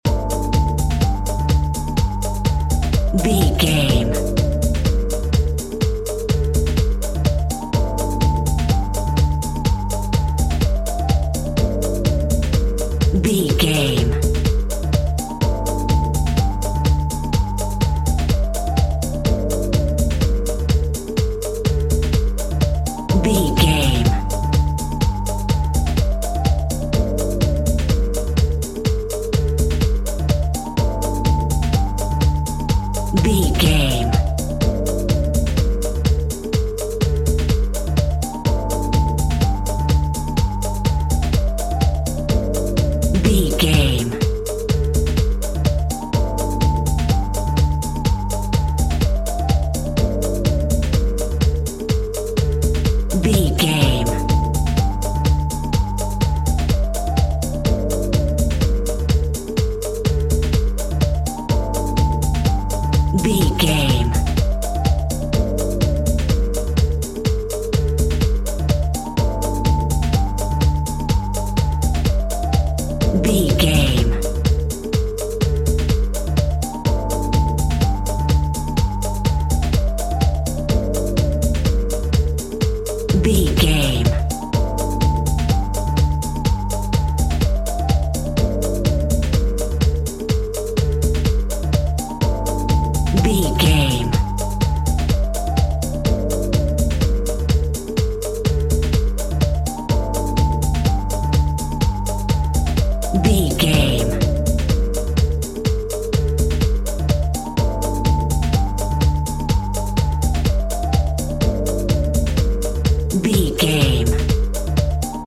Aeolian/Minor
Funk
groove
jazz funk
exciting
electric guitar
wah clavinet
fender rhodes
hammond organ
funk drums
funky bass
horns
saxophones
percussion